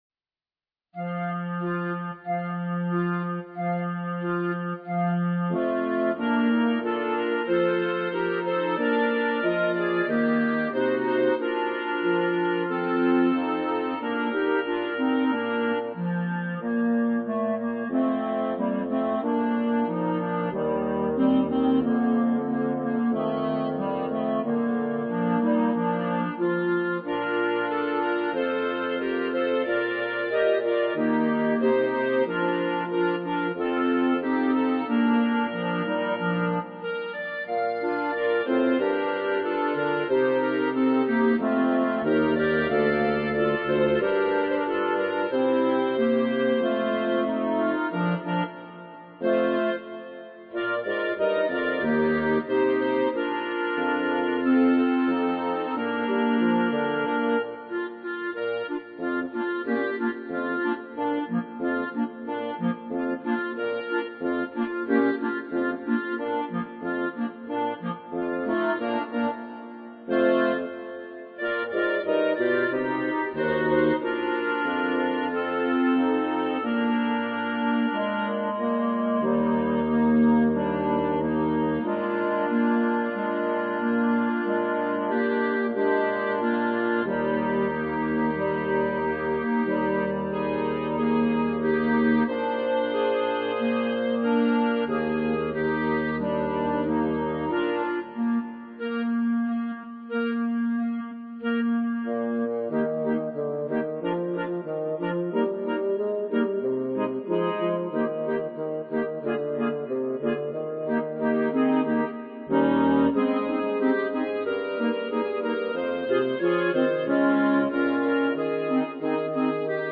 B♭ Clarinet 1 B♭ Clarinet 2 B♭ Clarinet 3 Bass Clarinet
单簧管四重奏
童谣